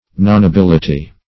Meaning of nonability. nonability synonyms, pronunciation, spelling and more from Free Dictionary.